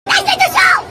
explode4.ogg